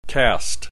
click this icon to hear the preceding term pronounced